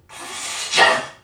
NPC_Creatures_Vocalisations_Robothead [92].wav